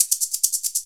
Shaker 05.wav